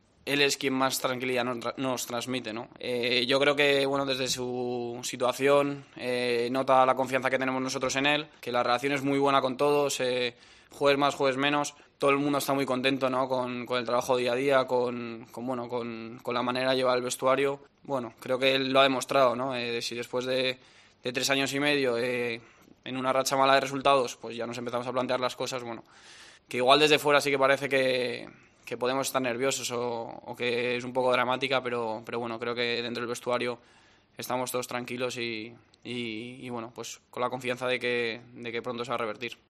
Todo el mundo está muy contento con el trabajo día a día y la manera de llevar el vestuario”, dijo Melero en una rueda de prensa telemática.